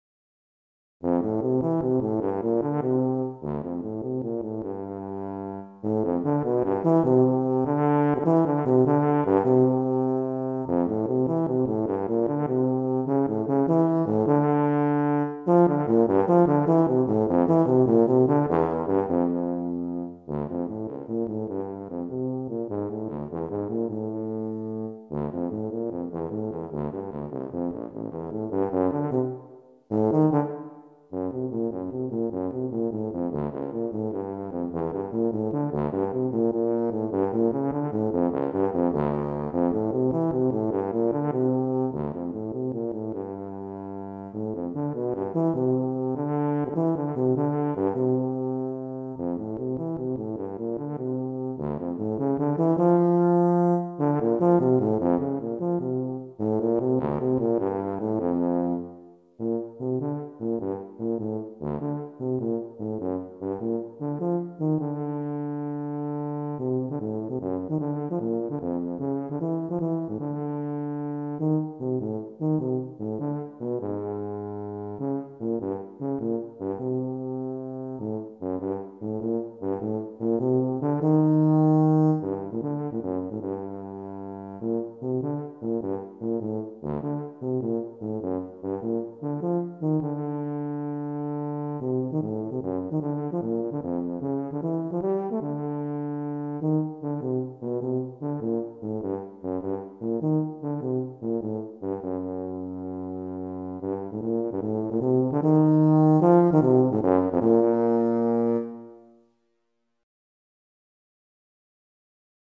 Voicing: Tuba Methods/Studies/Etudes